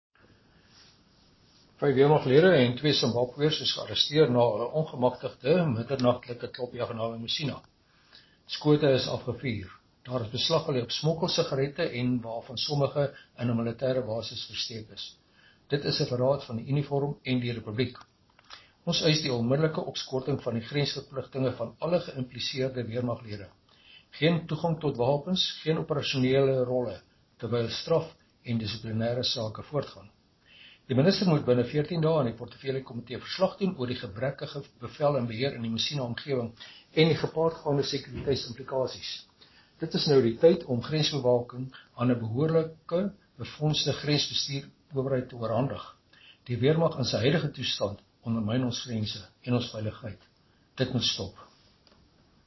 Issued by Chris Hattingh MP – DA Spokesperson on Defense and Military Veterans
Afrikaans soundbites by Chris Hattingh MP.